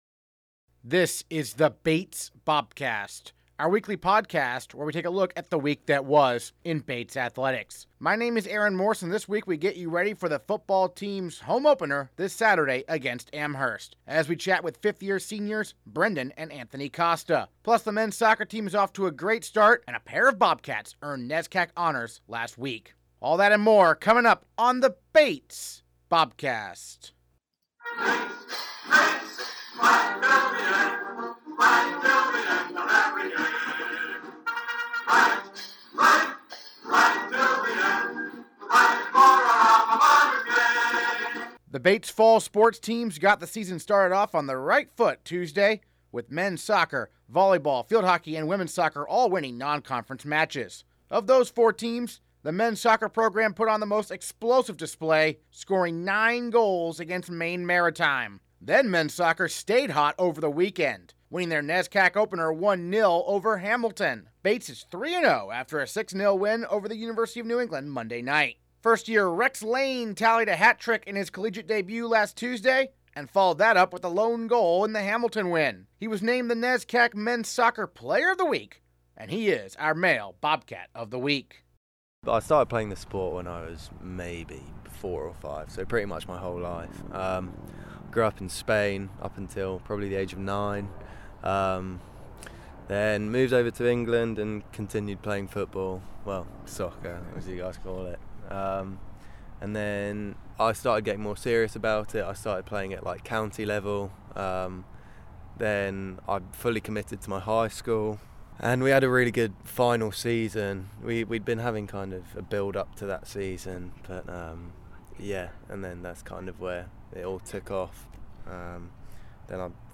Bobcast Transcript Interviews this episode